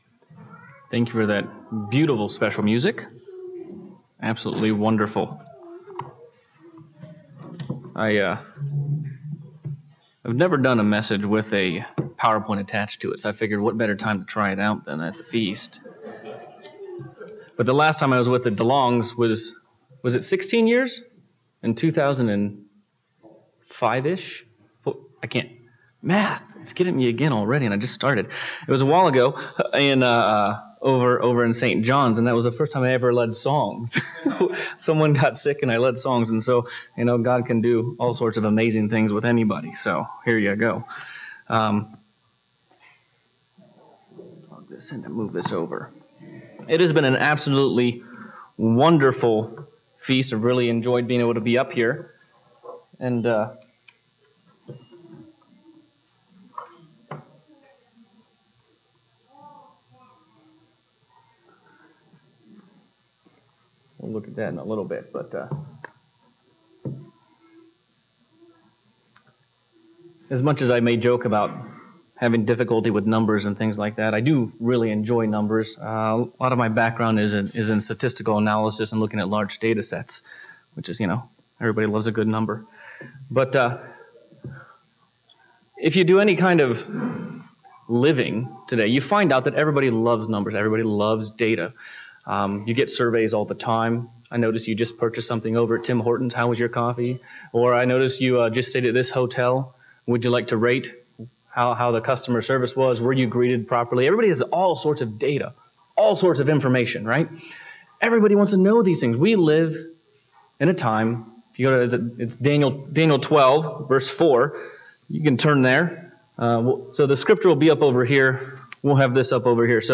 This sermon was given at the Cochrane, Alberta 2019 Feast site.